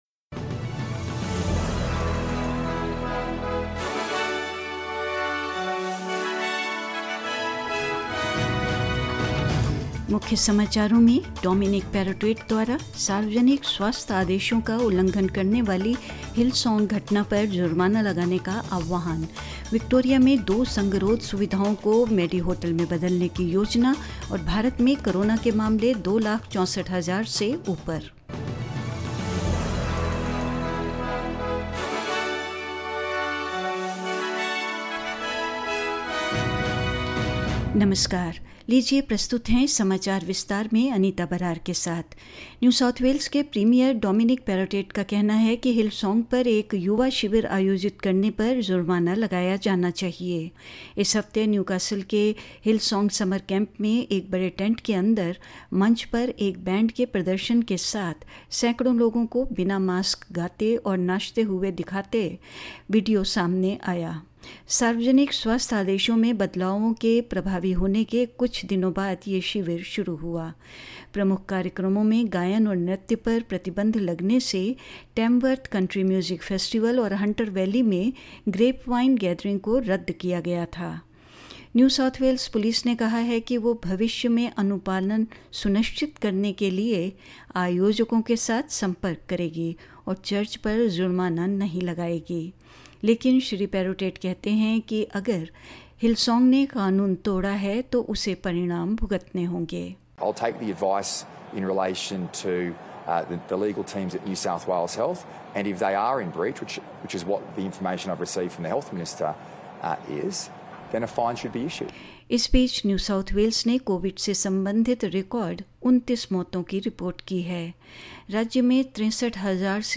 In this latest SBS Hindi bulletin: Dominic Perrottet calls for Hillsong to be fined over event that breached public health orders; Victoria to convert two quarantine facilities into medi-hotels to ease the burden on hospitals; Tasmania hosts its first ever men's Ashes Test and more news.